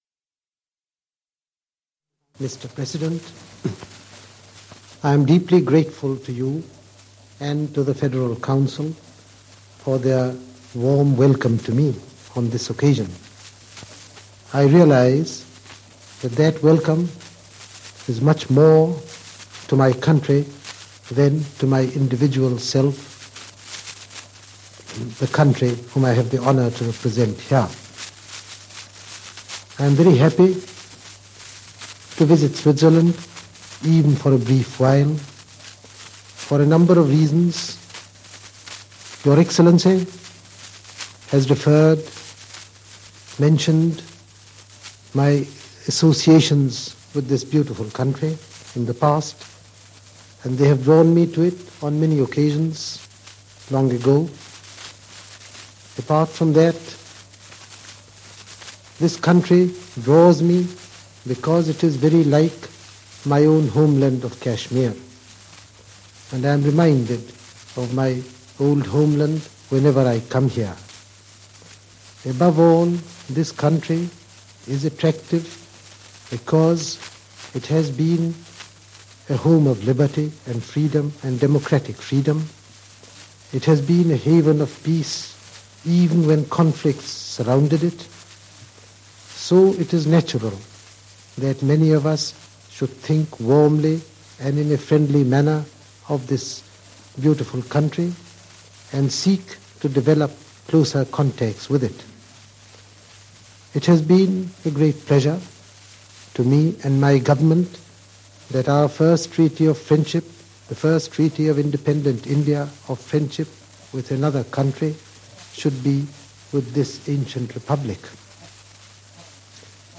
Speech by Indian Prime Minister Jawaharlal Nehru on a visit to Switzerland in May 1949
archive speech by Indian PM Nehru